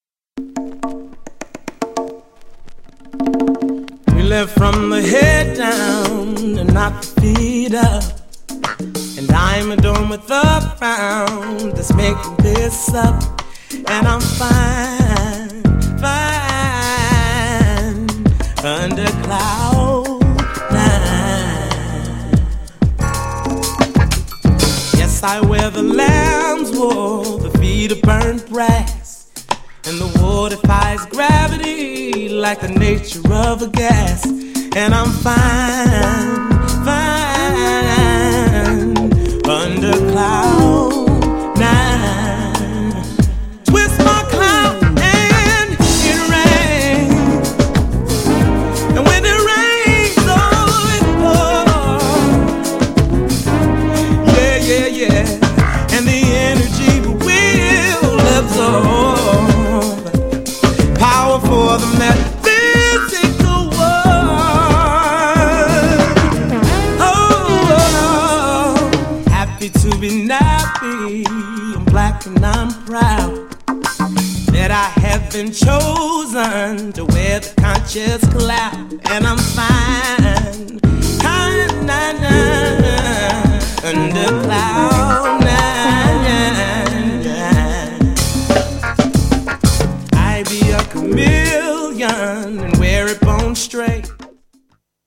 後世にも受け継がれるであろう現代のソウルミュージック!!
GENRE R&B
BPM 91〜95BPM
GROOVYなR&B
SMOOTH_R&B
ネオソウル # 男性VOCAL_R&B